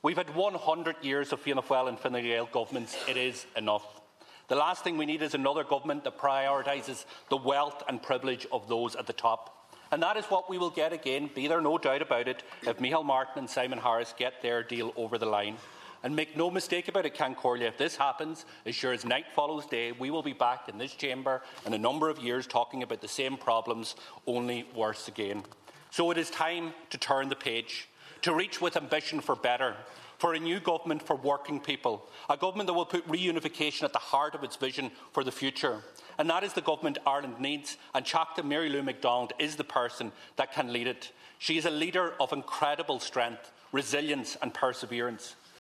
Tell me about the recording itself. He told the Dail that a new Government is needed to deliver change: